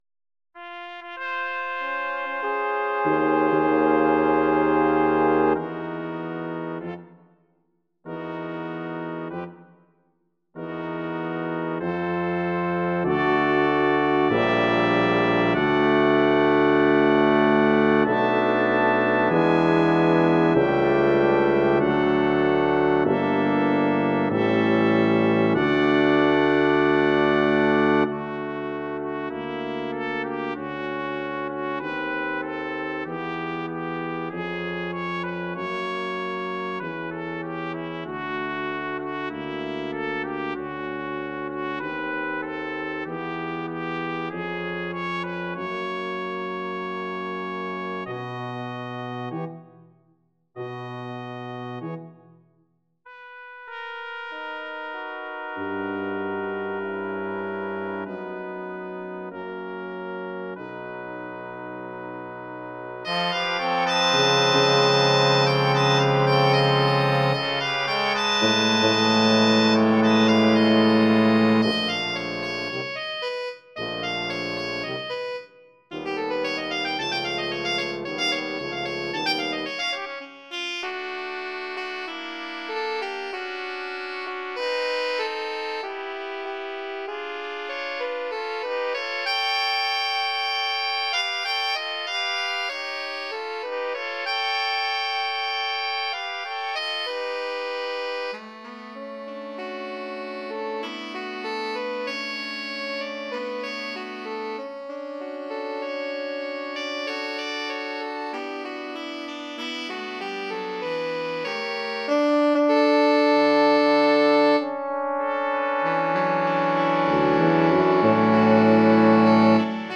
mp3 (keyboard sound) Kies mp3 bestand.